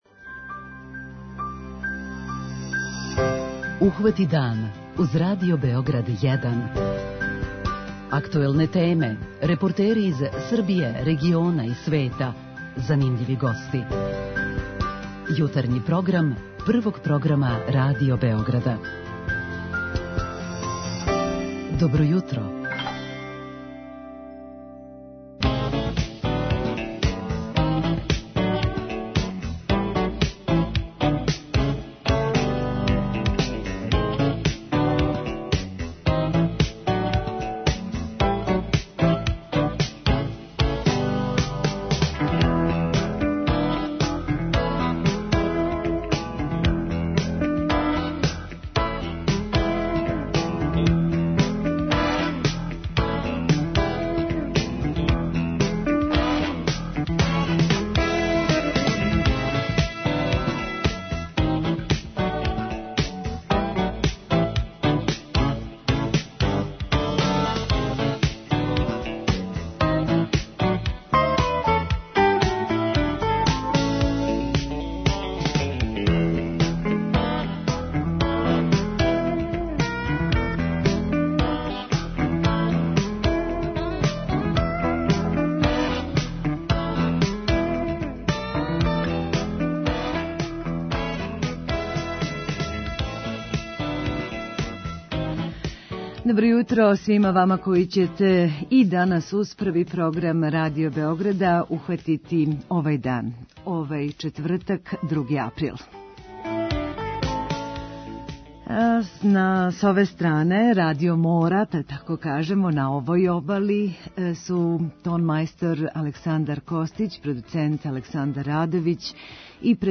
Гошћа телефоном